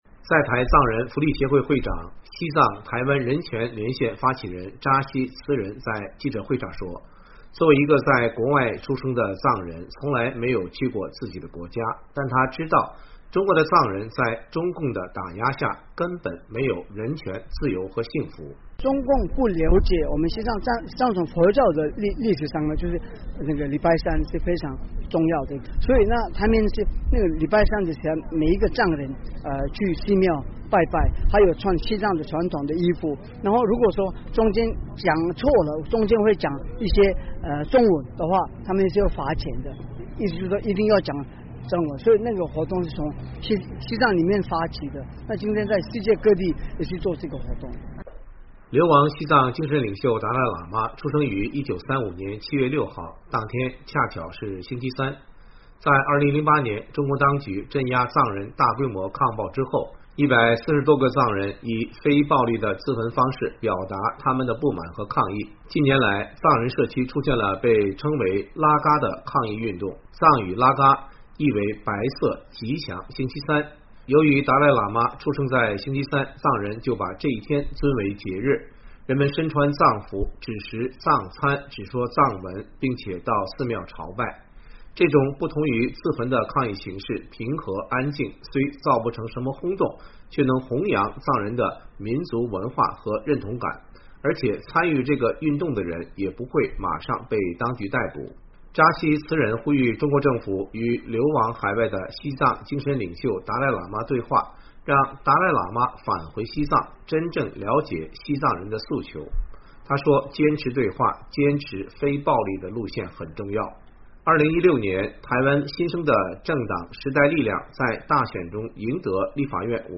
几十名带着西藏国旗的藏人骑自行车到中正纪念堂所在地的自由广场，举行2017年为西藏自由而骑Cycling For a Free Tibet的活动，为西藏的自由、民主和独立发声。
台湾时代力量党立法委员林昶佐